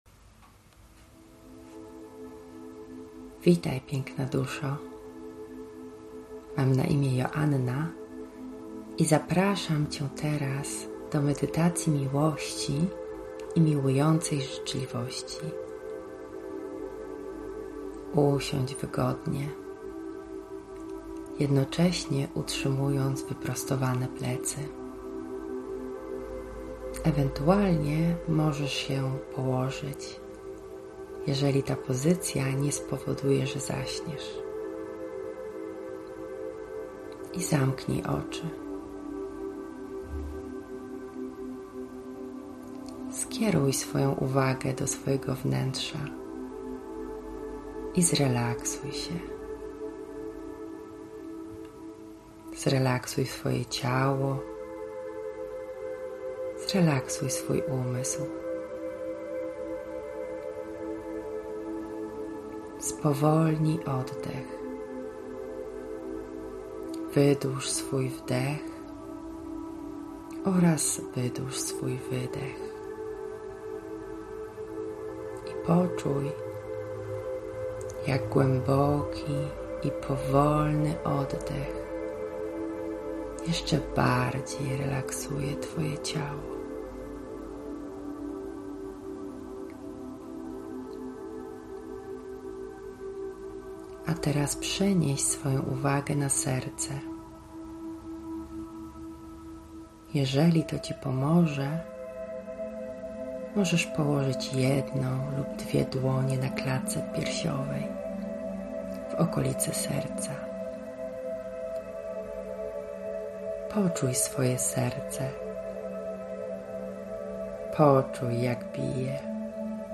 medytacja_milosci-gyBdm3uo1gYXy9zq.mp3